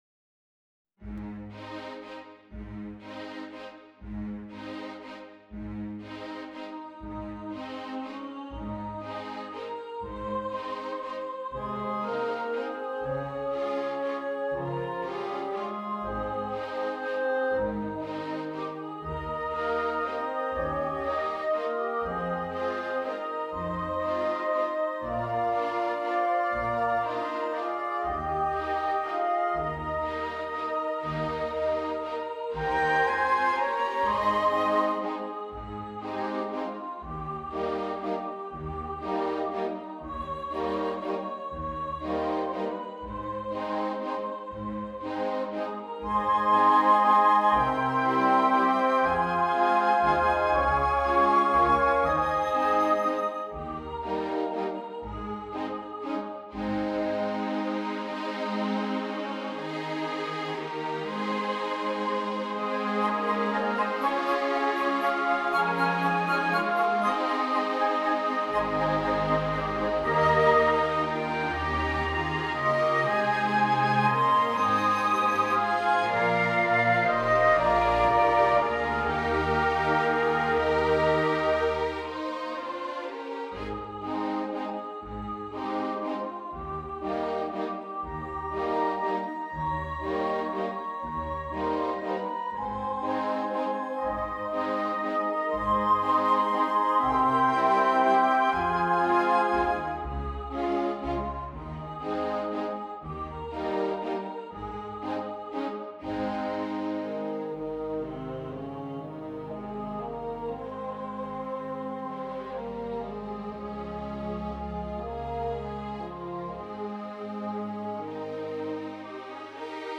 Lead vocal
2 Flutes
Oboe
2 Clarinets
Bassoon
3 Horns
Viola
Cello
Double Bass